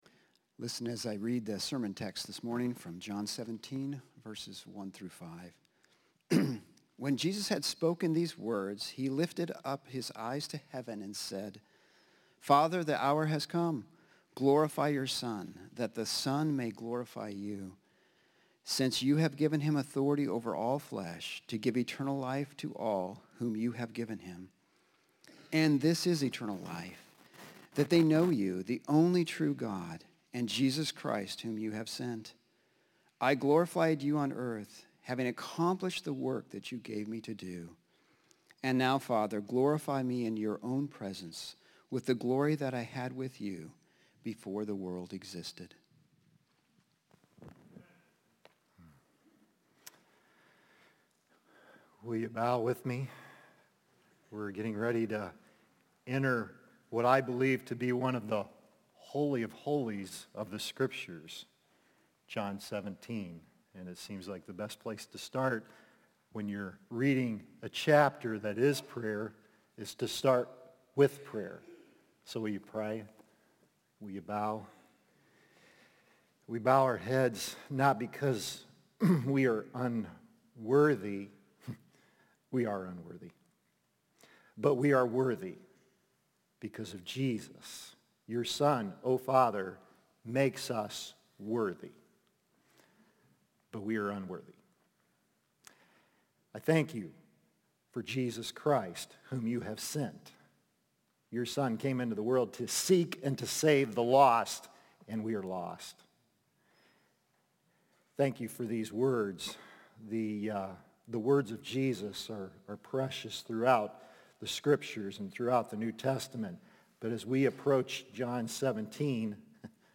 Until I Return, Glorify God | Baptist Church in Jamestown, Ohio, dedicated to a spirit of unity, prayer, and spiritual growth